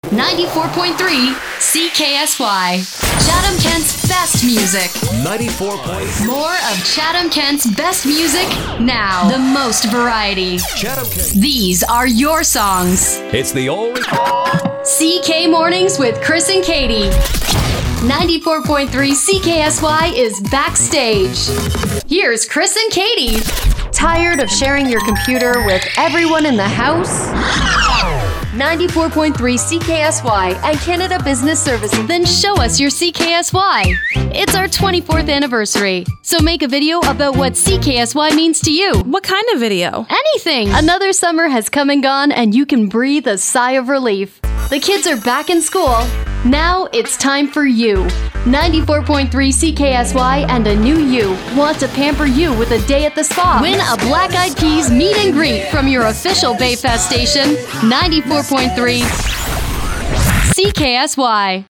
Female
My voice is best described as perky, enthusiastic, and bubbly. I have a "cool mom" kind of sound, and love any scripts that call for a bit of sarcasm.
Radio Commercials
Words that describe my voice are friendly, announcer, upbeat.